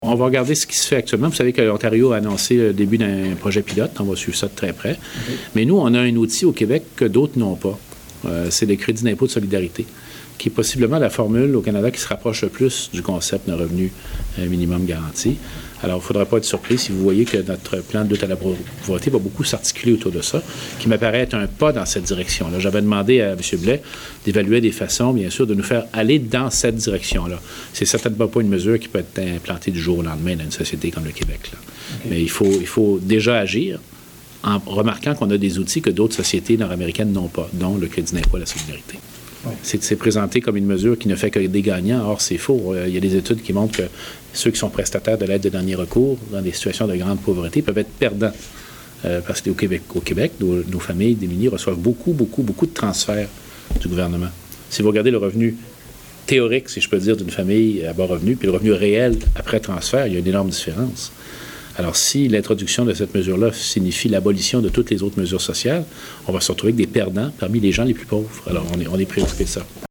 Lors de son passage à Trois-Rivières jeudi dernier, le premier ministre du Québec Philippe Couillard a réservé quelques minutes de son horaire bien chargé pour accorder une entrevue à la Gazette de la Mauricie.